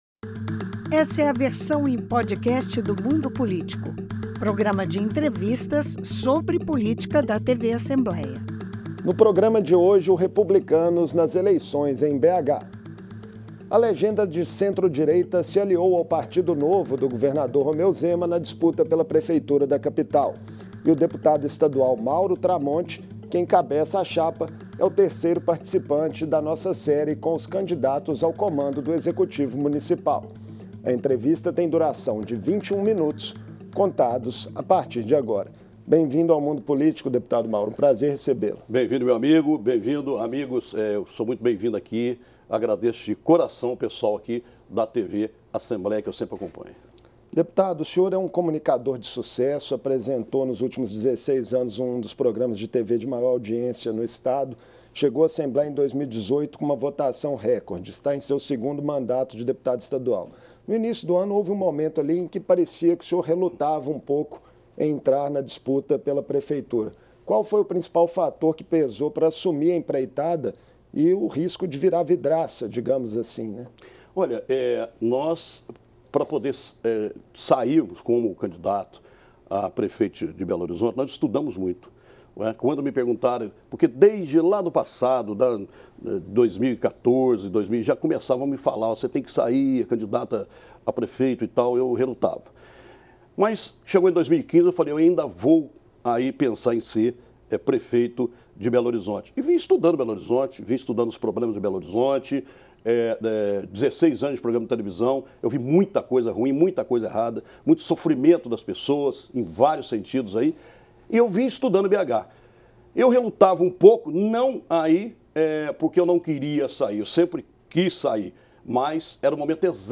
O Mundo Político faz a terceira entrevista da série com os postulantes à prefeitura de Belo Horizonte.